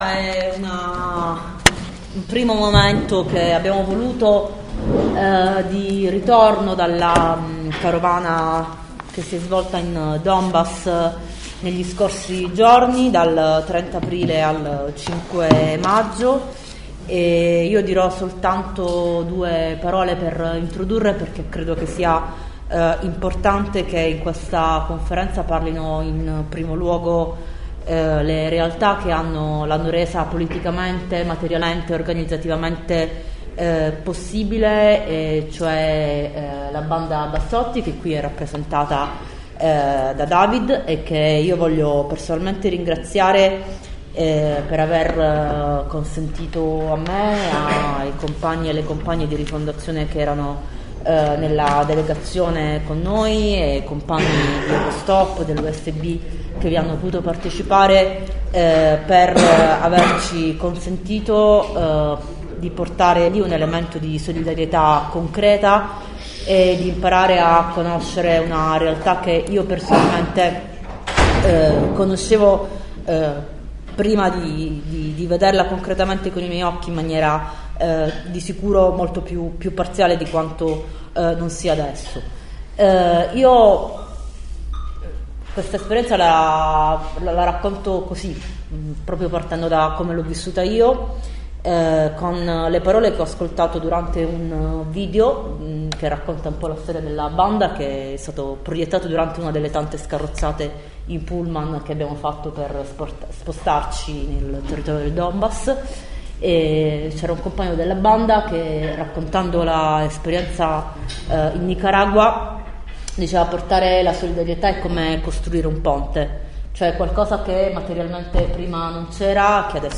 Conferenza stampa: di ritorno dal Donbass – Eleonora Forenza